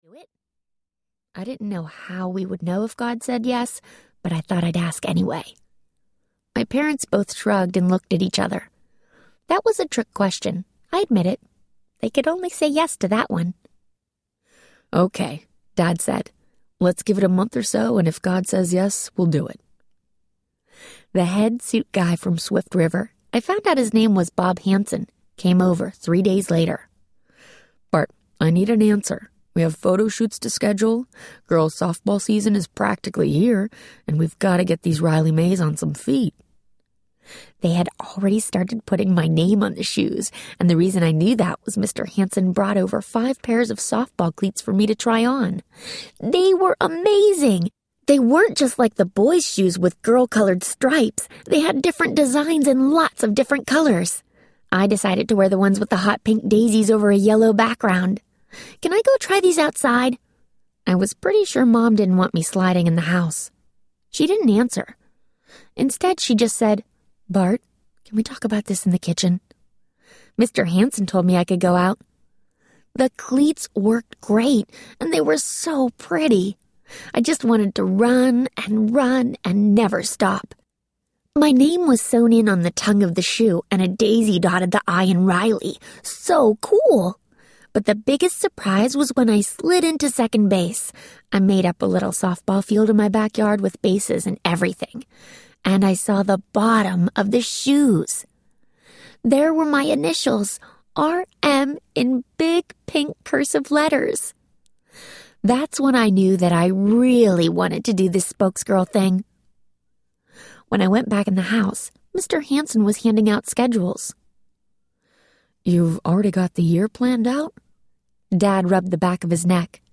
Riley Mae and the Rock Shocker Trek Audiobook
Narrator
4.25 Hrs. – Unabridged